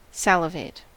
Ääntäminen
Synonyymit (halventava) drool Ääntäminen US Tuntematon aksentti: IPA : /ˈsælɪveɪt/ Haettu sana löytyi näillä lähdekielillä: englanti Käännös Verbit 1. salivar Määritelmät Verbit (intransitive) To produce saliva .